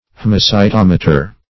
Haemocytometer \H[ae]m`o*cy*tom"e*ter\
haemocytometer.mp3